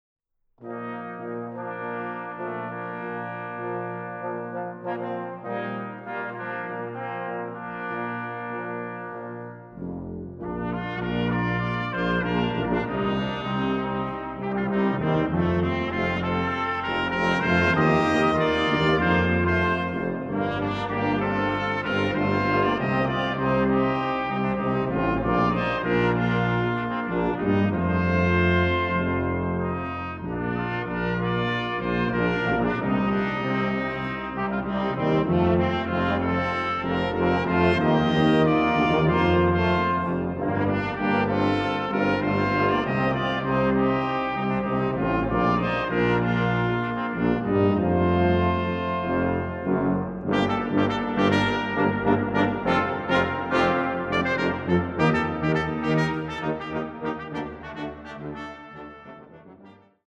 Music for trombone choir and brass ensemble
Brass ensemble